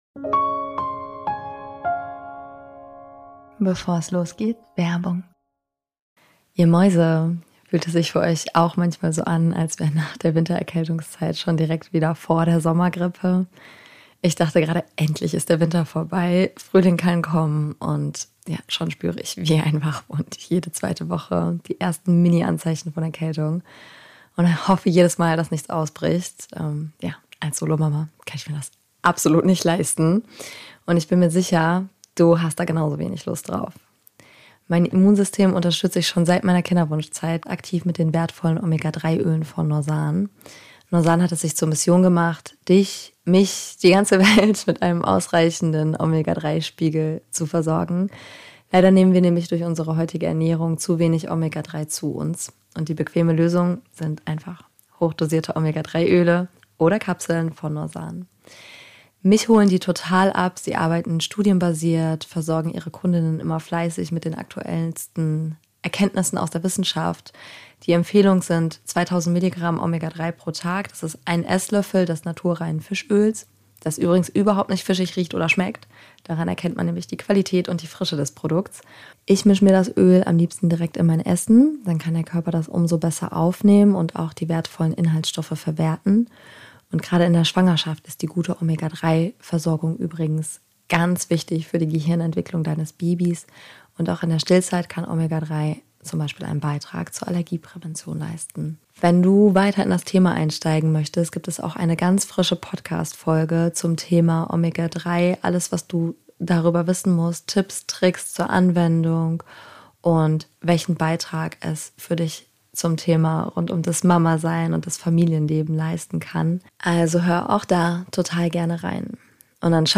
Und hatte das Bedürfnis einige gerade der älteren Meditationen upzudaten. Manchmal gefällt mir die Wortwahl einfach nicht mehr oder die Technik Qualität hat sich mittlerweile einfach schon sehr verbessert.